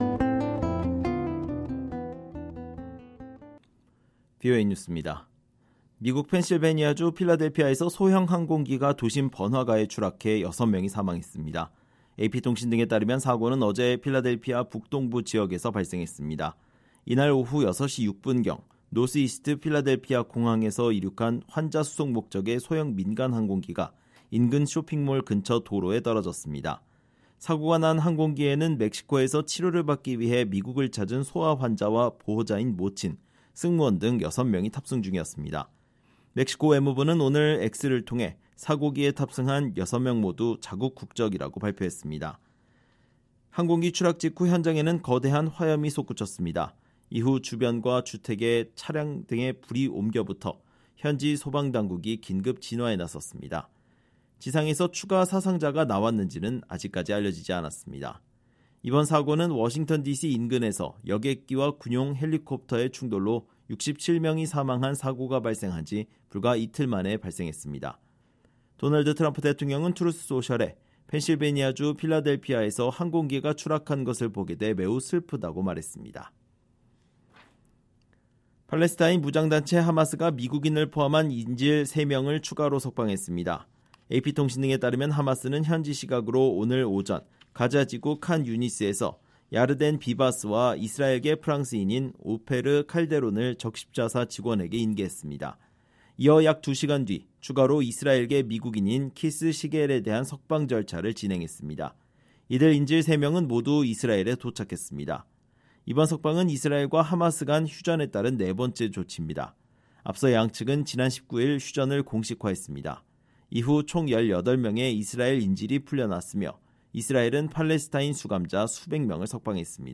VOA 한국어 방송의 토요일 오후 프로그램 2부입니다. 한반도 시간 오후 9:00 부터 10:00 까지 방송됩니다.